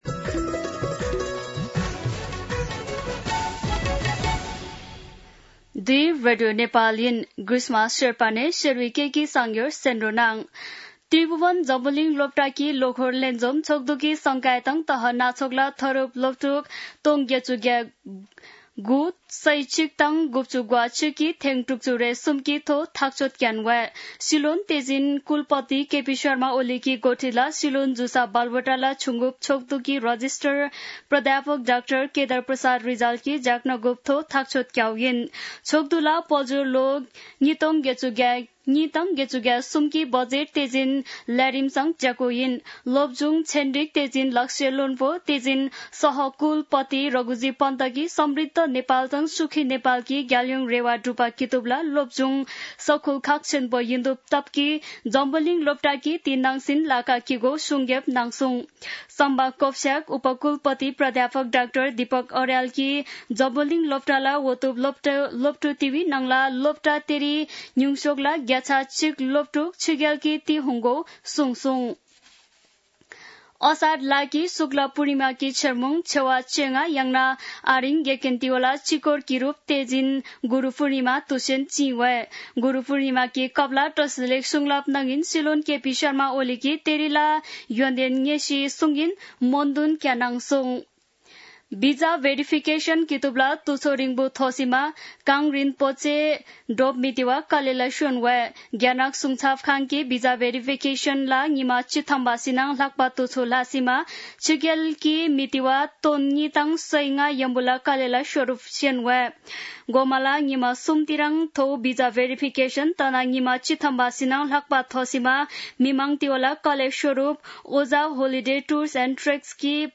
शेर्पा भाषाको समाचार : २६ असार , २०८२
Sherpa-News-3-26.mp3